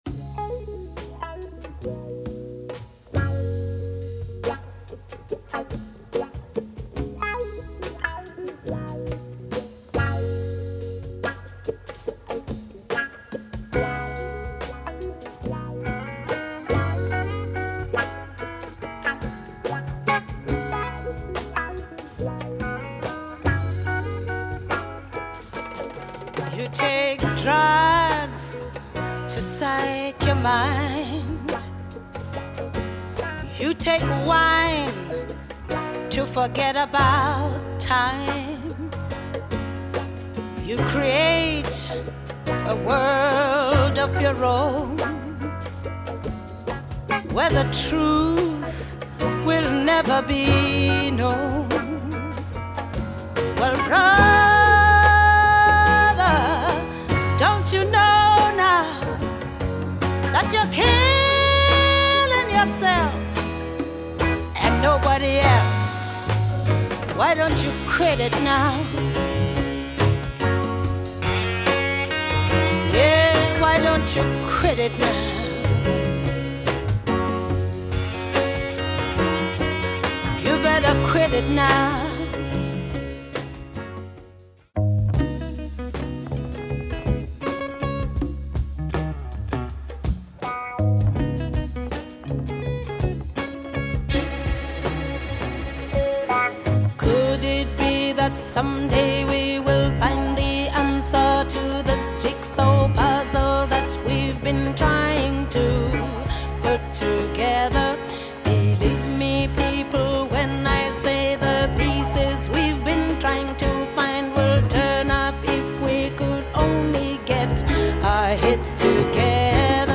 a marvellous soul downtempo that has smashing samples